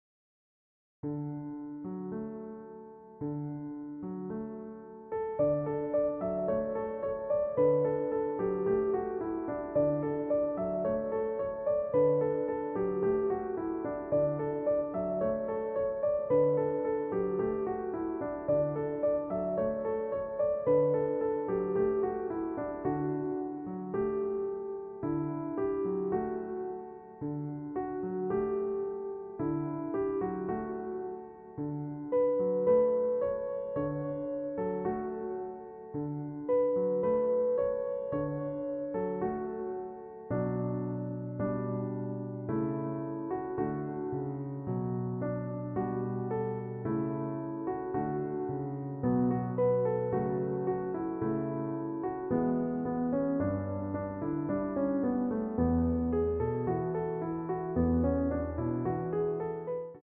EASY MEDIUM Piano Tutorial